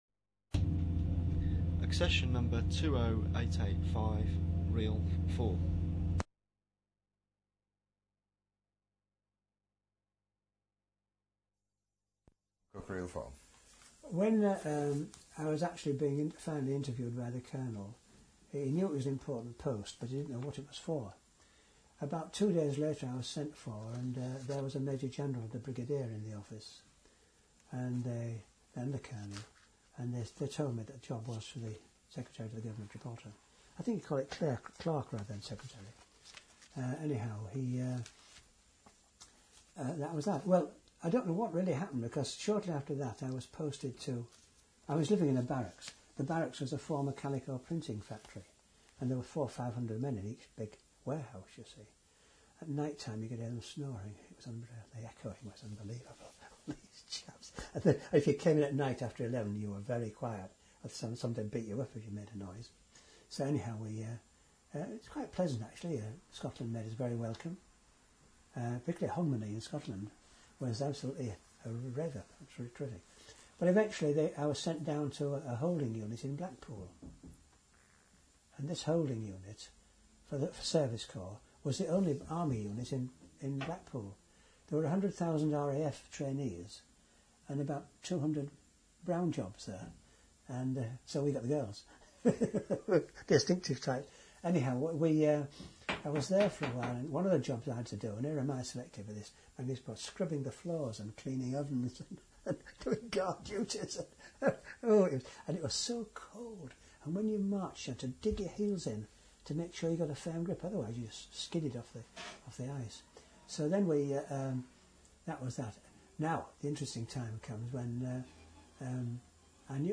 An eloquent and engaging speaker, he describes the troopship to Gibraltar, his arrival and commencing work at Government House.